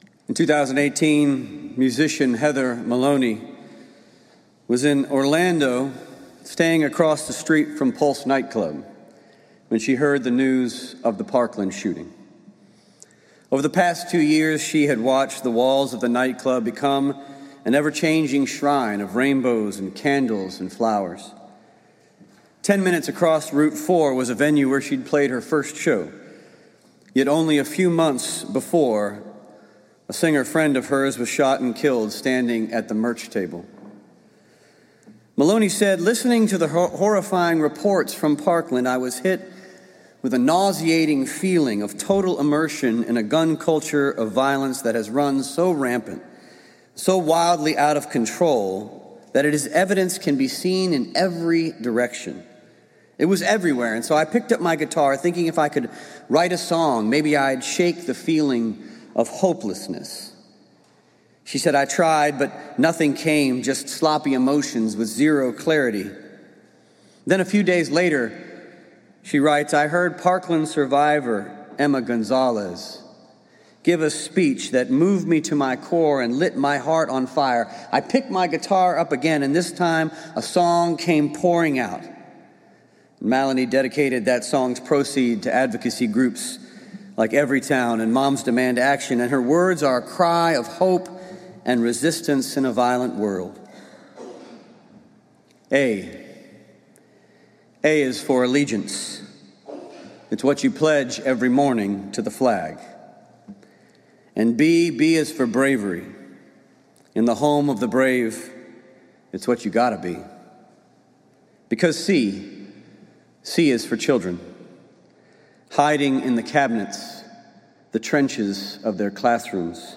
Gun Violence Awareness Sunday, Wear Orange